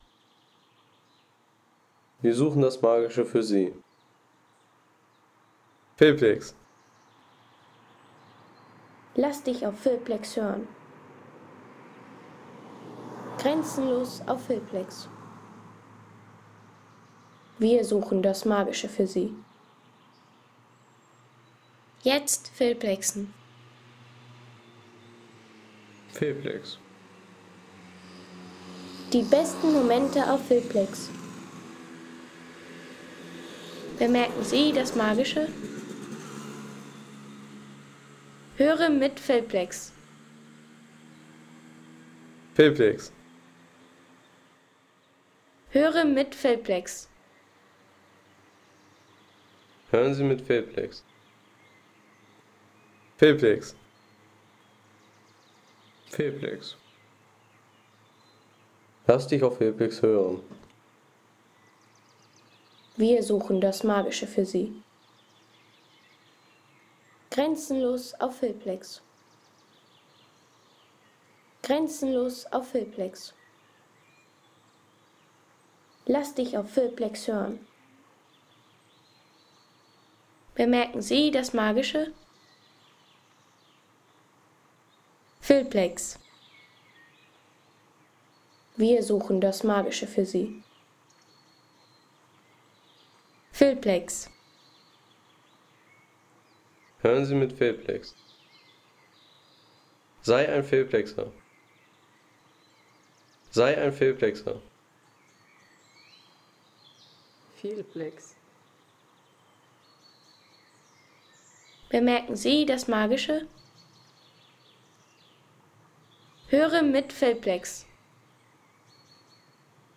Waldatmosphäre aus der Sächsischen Schweiz
Sächsische Schweiz | Natürliche Waldatmosphäre
Authentische Atmosphäre aus der Sächsischen Schweiz mit Waldgeräuschen, Vogelstimmen und dezenten Umgebungsgeräuschen.
Hol dir die ruhige Atmosphäre der Sächsischen Schweiz in Filme, Reisevideos, Sound-Postkarten und dokumentarische Szenen.